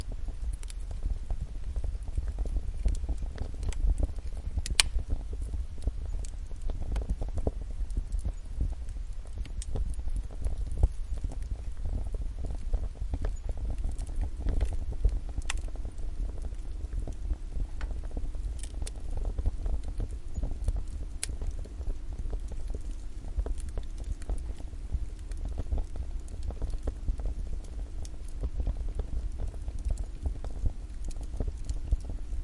描述：Fire sounds including sounds of roaring flame and crackling. Recorded on a Rode mic and Zoom H4N Pro.
标签： fireplace flame crackle fire burning flames
声道立体声